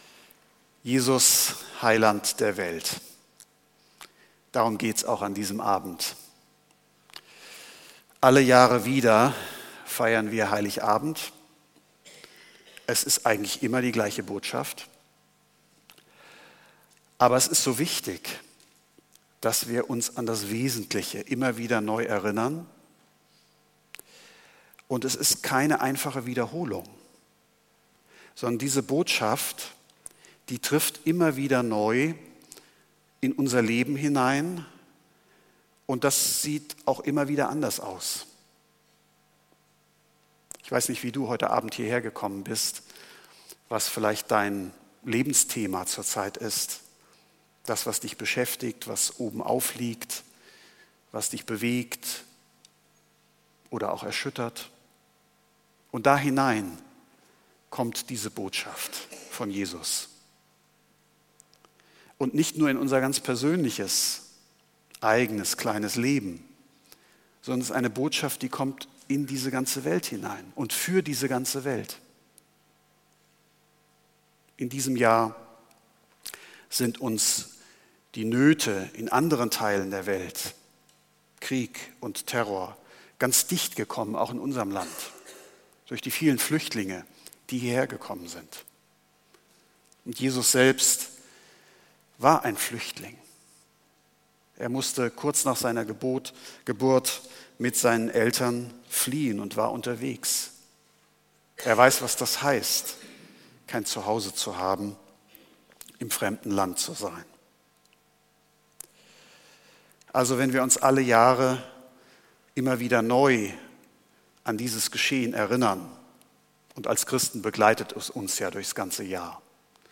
Weihnachten 2015 – Besinnlicher Gottesdienst Predigttext: Jesaja 42,1-7